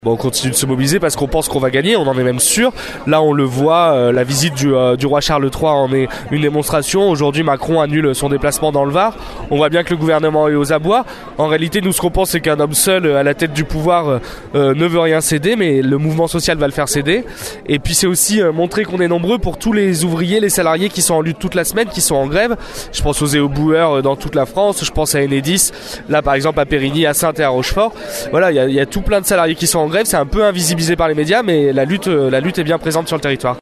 Hier matin, lors du rassemblement place Colbert à Rochefort.